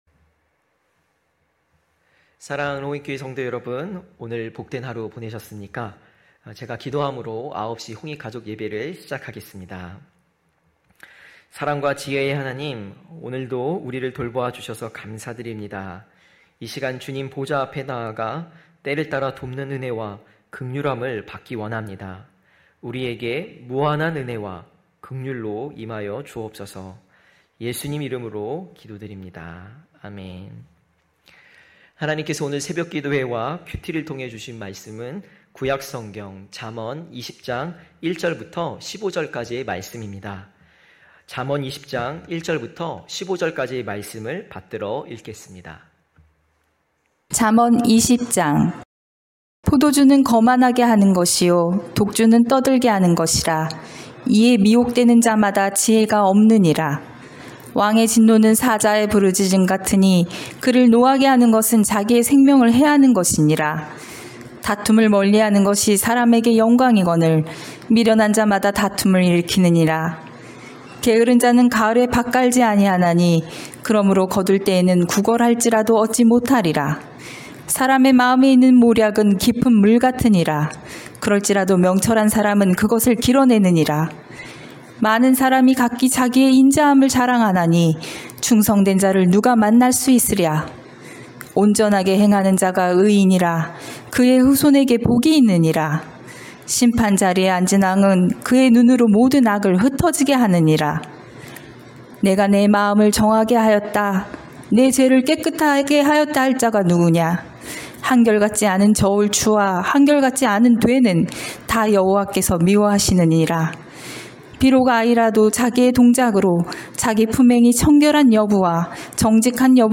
9시홍익가족예배(6월7일).mp3